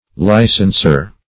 Licenser \Li"cens*er\ (l[imac]"sens*[~e]r), n.